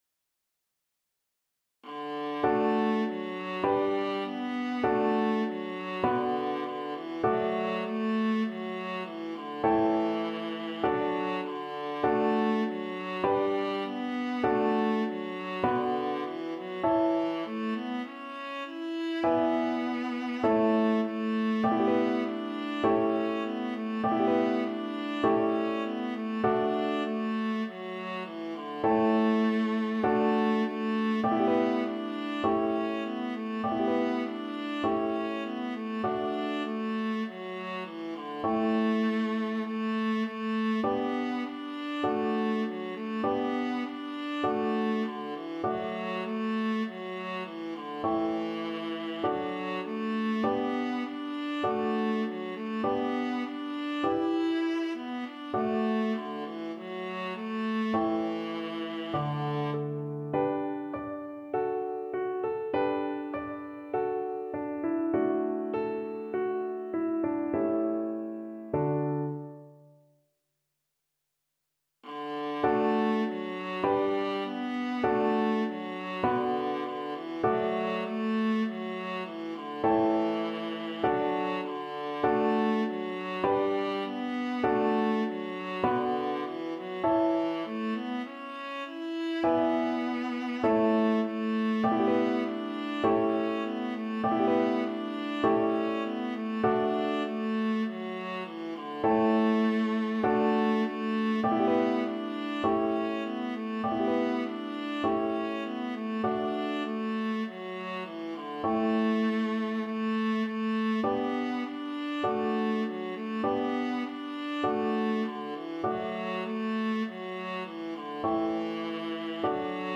4/4 (View more 4/4 Music)
Moderato
Classical (View more Classical Viola Music)